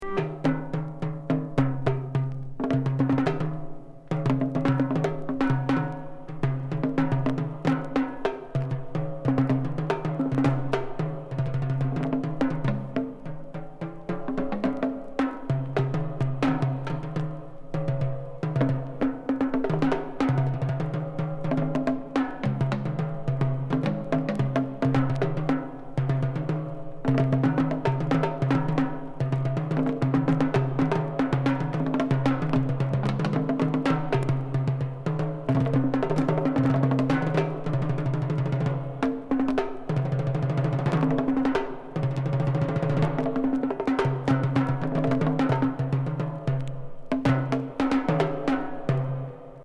ギリシャ産、長編地中海イージー＆ムード・ミュージック時々サイケ、後
トライバルな激珍盤72年作！！